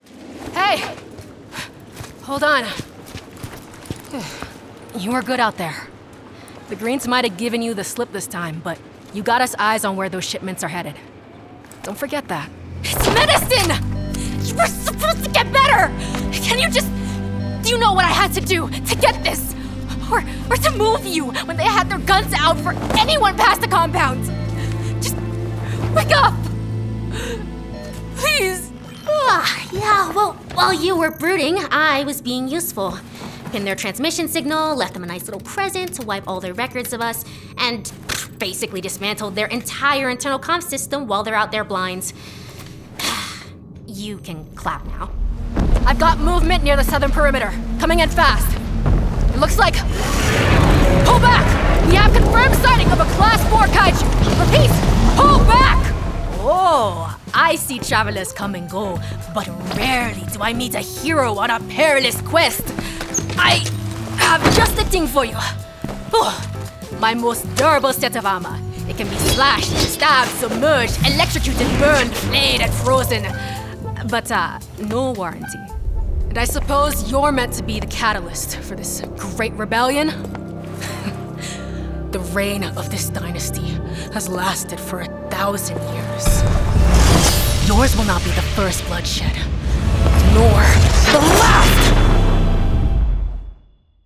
Gen Z, African-American voice over artist for commercials, video games, animation, narration & more. Broadcast-quality home studio.